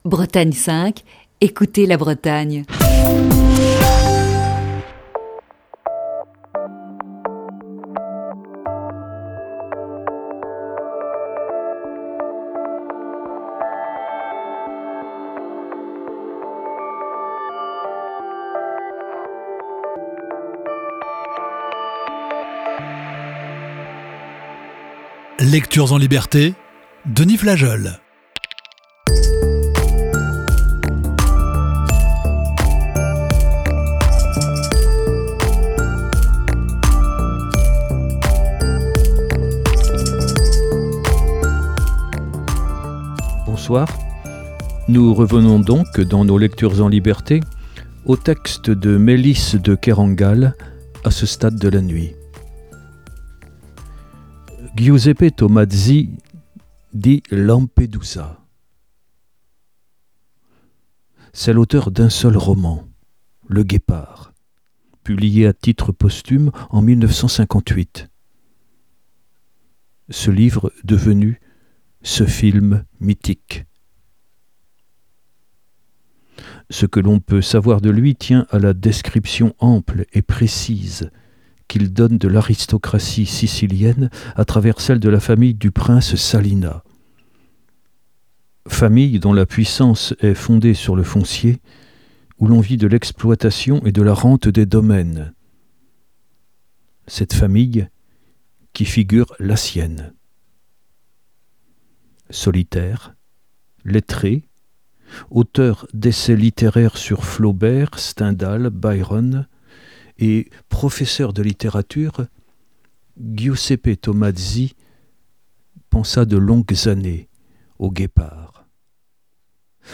Émission du 19 mai 2020.